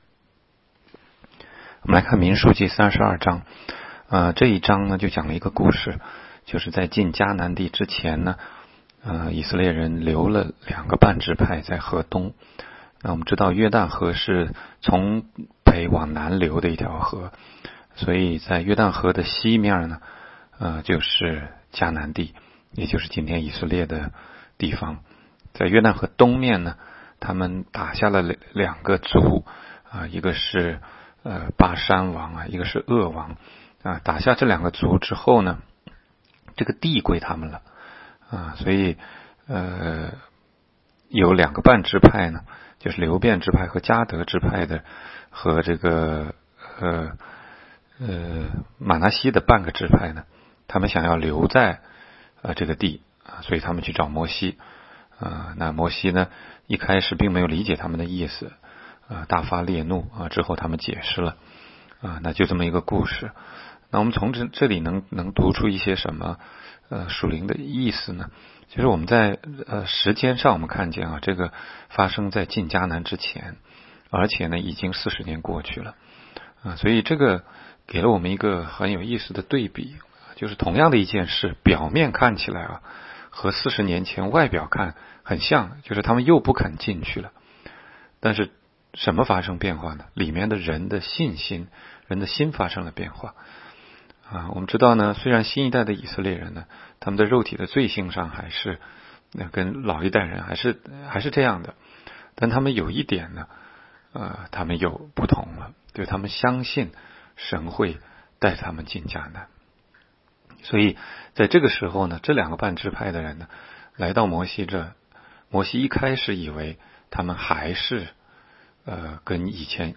16街讲道录音 - 每日读经-《民数记》32章